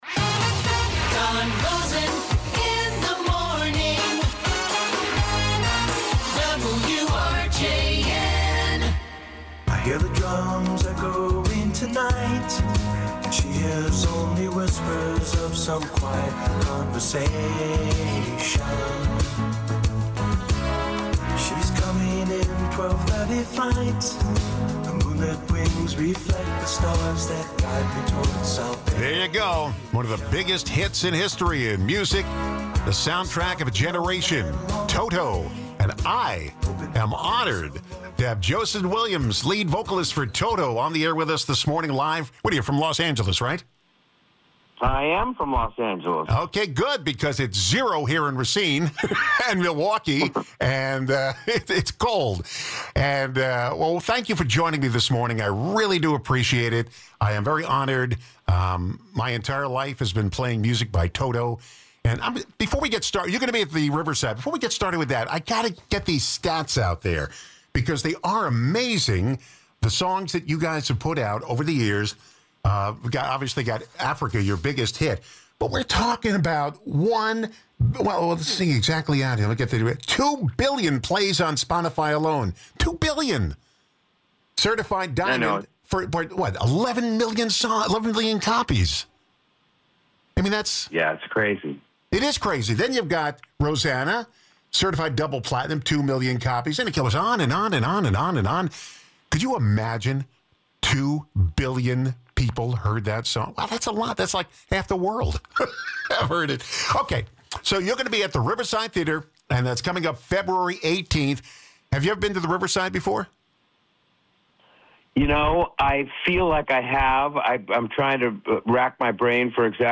Guests: Joseph Williams